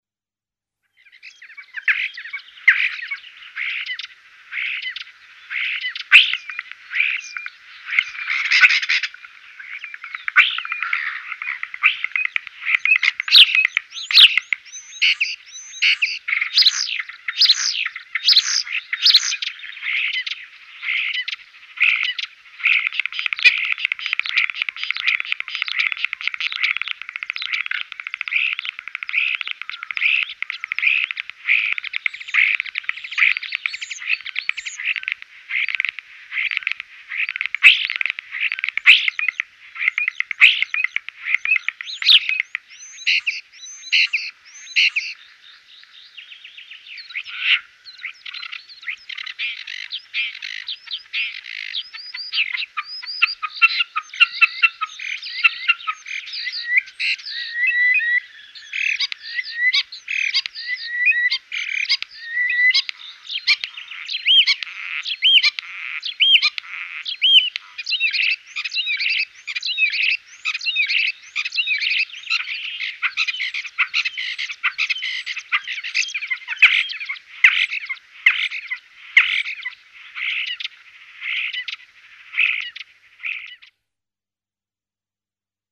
Chant :
Étourneau sansonnet
Leur chant varie des sifflets mélodieux, des cris rauques jusqu'aux imitations réussies de sons tels que les chants d'autres oiseaux, les téléphones, les carillons de porte et les klaxons.
L'étourneau sansonnet qui s'envole émet un « tcheerr » criard, bourdonnant et descendant.
Le chant de l'étourneau sansonnet est très varié, mélange de gazouillis, cliquetis, grincements, bavardages et gargouillements, intercalés de sifflements clairs souvent descendants « wheeeeeeeeooooooooooooo ».
81Starling.mp3